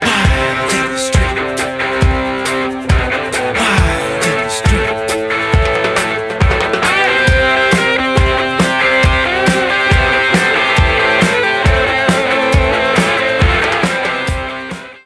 at Air Studios in London in 1976/1977